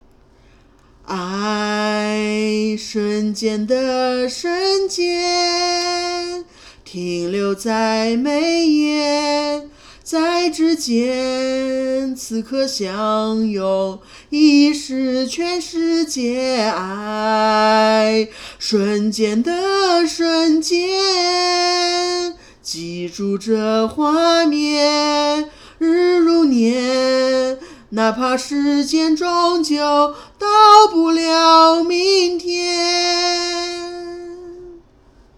练了几句